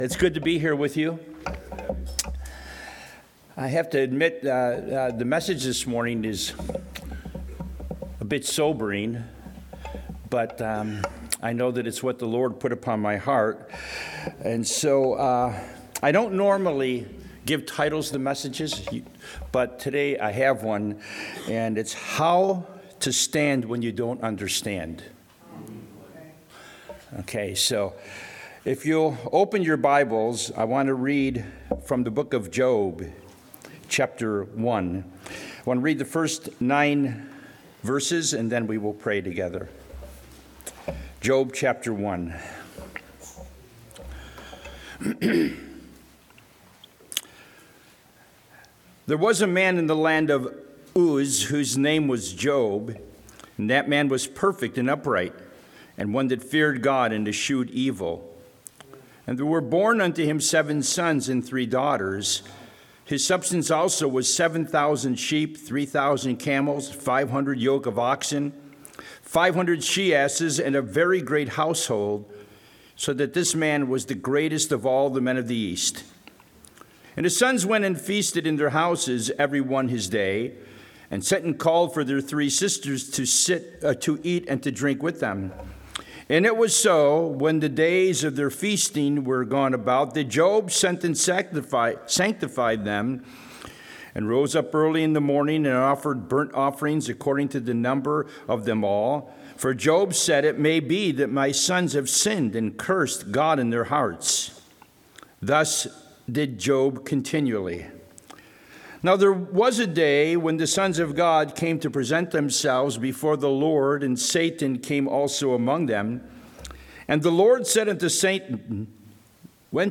Check out the most recent bible study message or use the tools provided to browse our archives of Topics, Speakers, or books of the Bible.